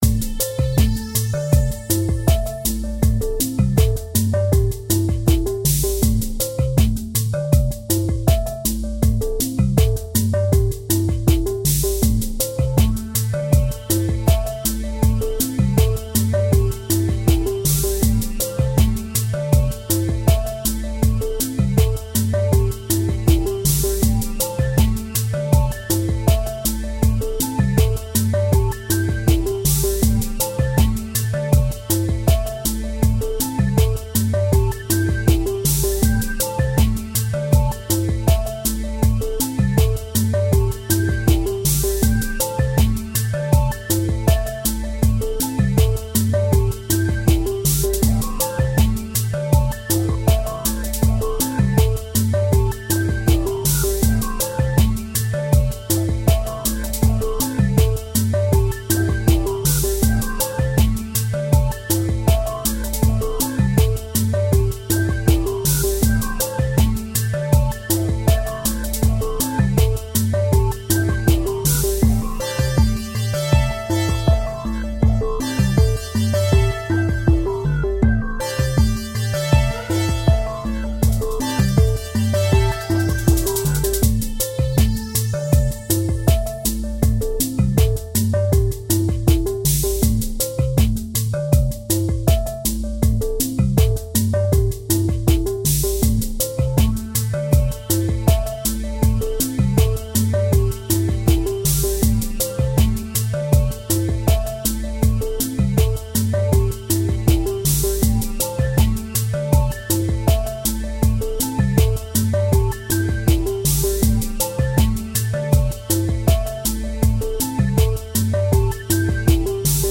Лаундж музыка на повторе, просто включите и расслабьтесь